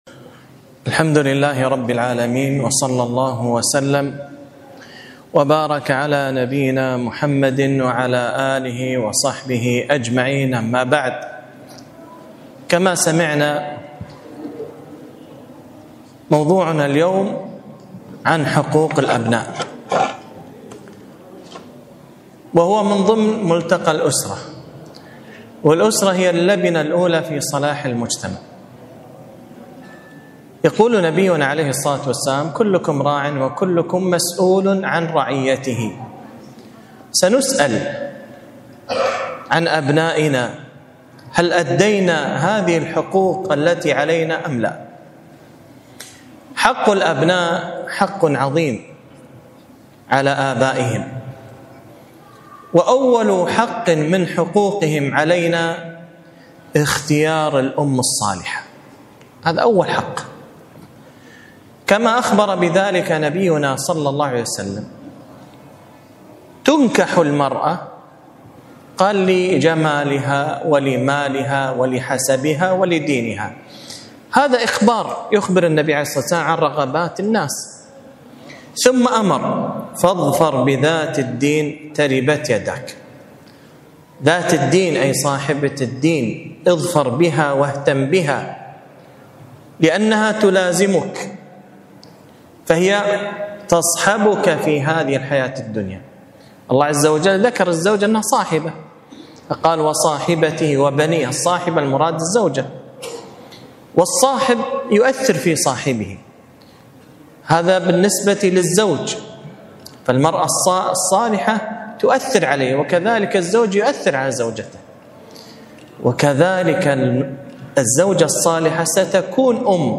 كلمة - حقوق الأبناء